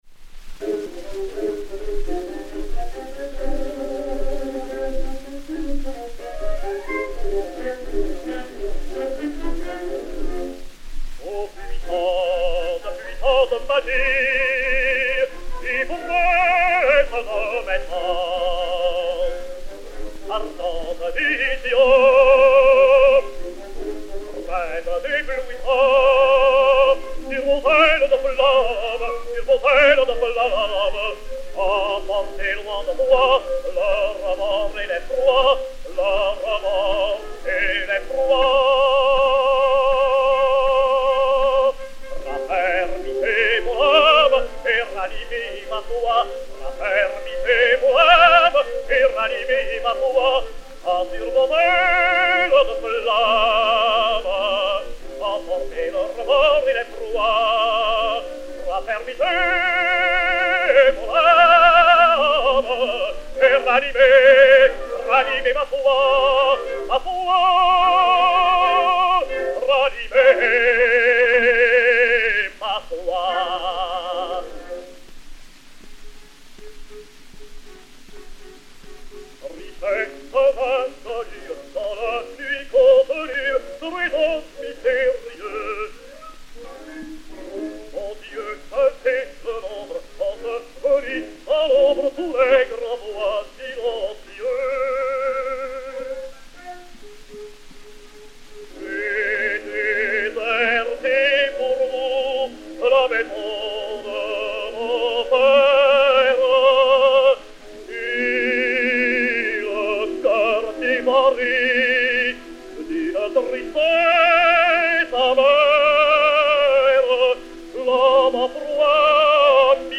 Henri Albers (Hoël) et Orchestre